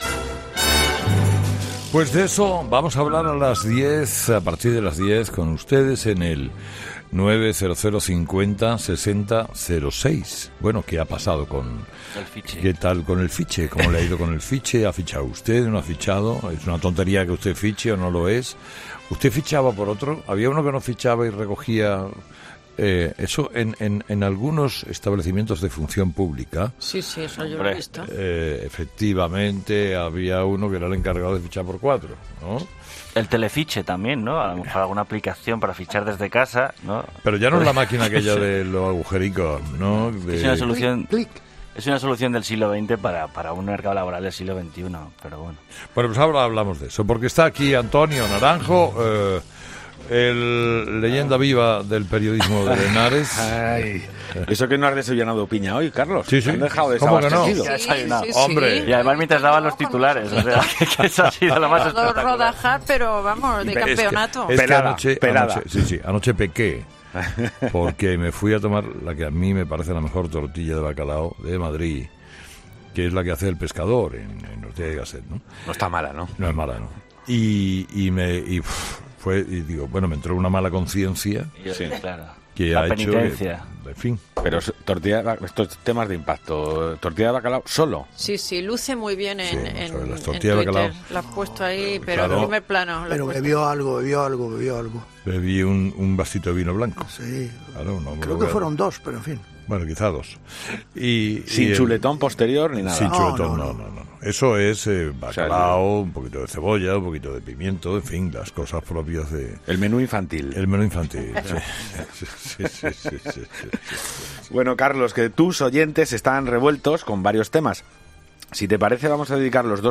Cada mañana, la audiencia habla con Carlos Herrera en 'La tertulia de los oyentes',